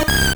Cri de Toudoudou dans Pokémon Or et Argent.